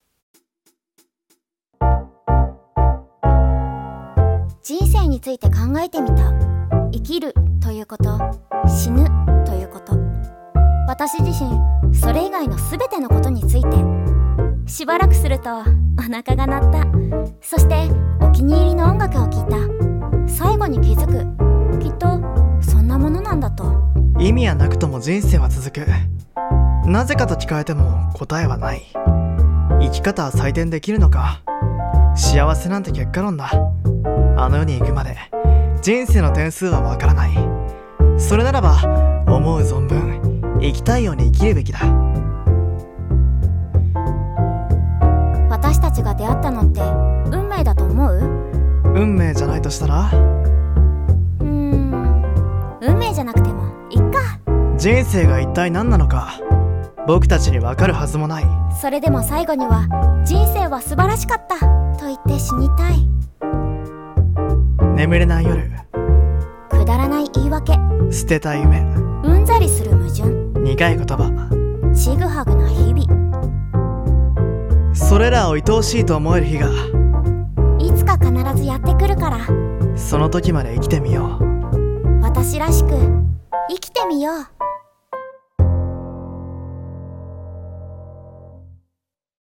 【声劇】About Life